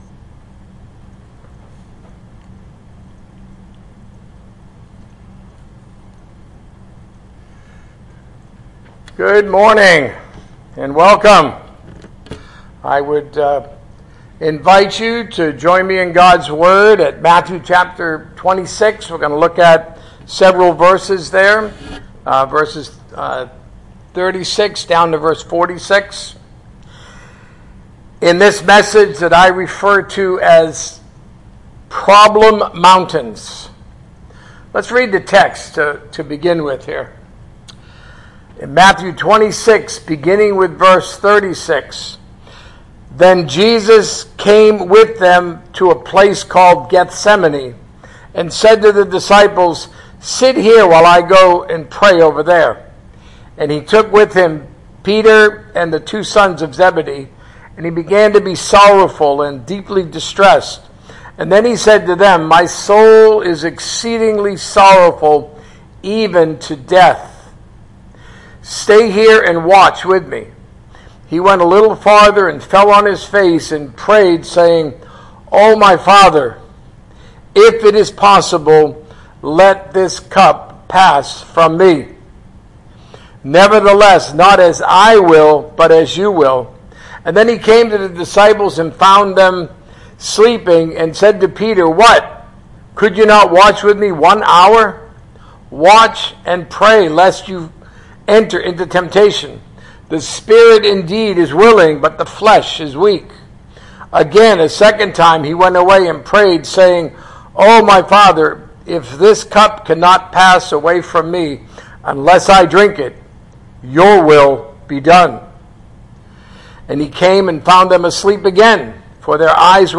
A message from the series "Serving Jesus."